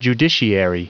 Prononciation du mot judiciary en anglais (fichier audio)
Prononciation du mot : judiciary